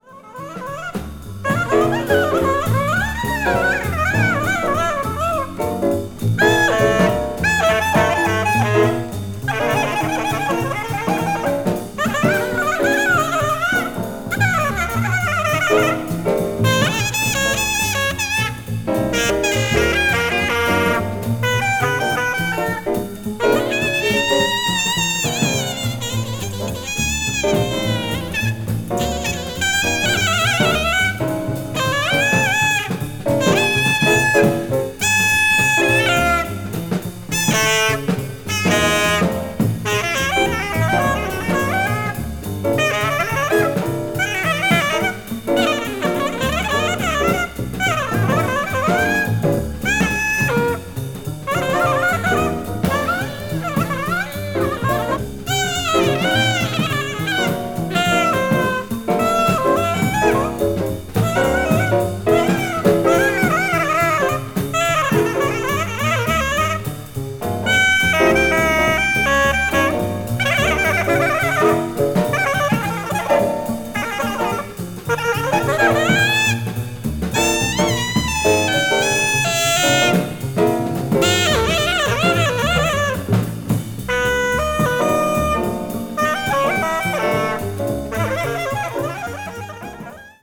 誠実に演奏するリズム・セクションとの対比があまりにも面白過ぎ。